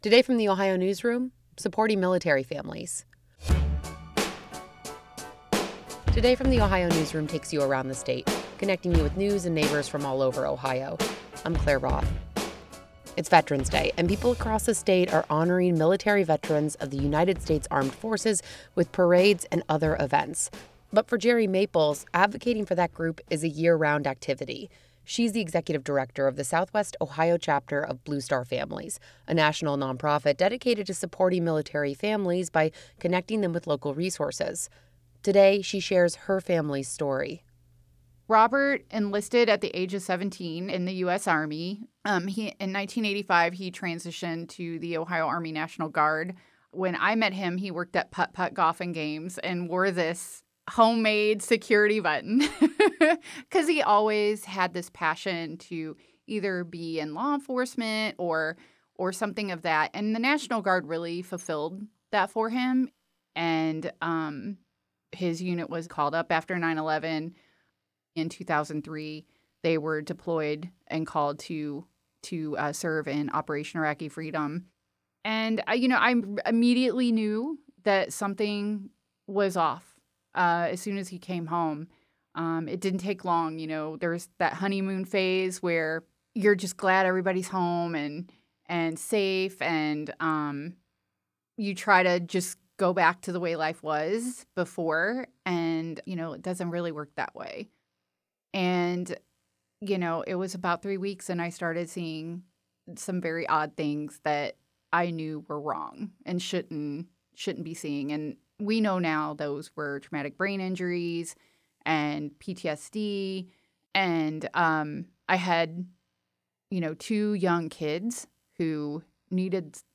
This interview has been edited for clarity.